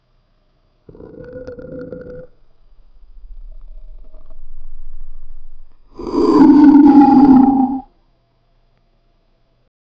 Groaning humanoid sound, trapped under skin, muffled, wet, distorted
groaning-humanoid-sound-t-rwqcvbbs.wav